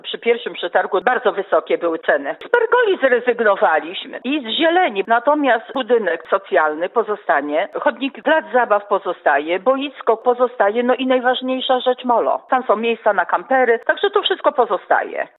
Zakres inwestycji został więc nieco okrojony, ale drugie podejście okazało się skuteczne – mówi wójt Lilia Ławicka.